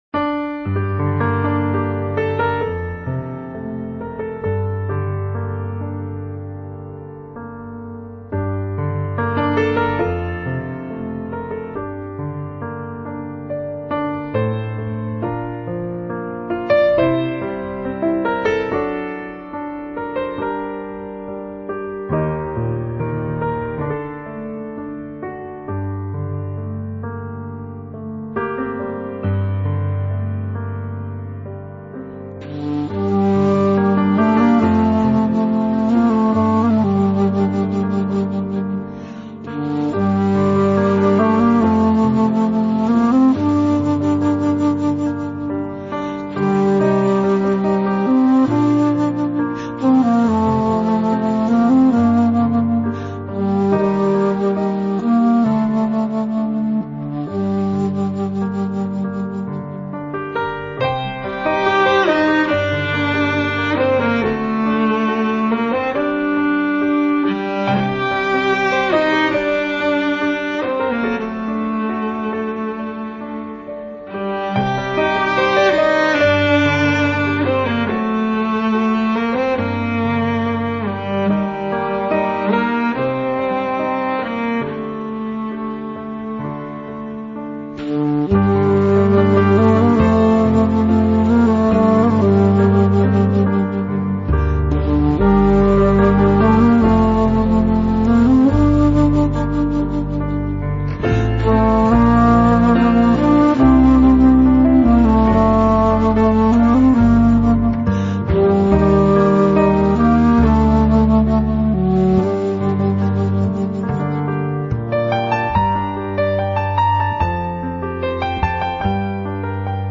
那悠悠的埙音，在暗夜中轻轻滑过， 美妙而触动人心，而那埙音却似人在呜咽……